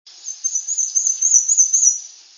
Given its very high and faint song, it is easiest to observe in late April combing branches for insects just before the leaves have unfolded and prior to the arrival of other much louder birds.
warbler_BW_740.wav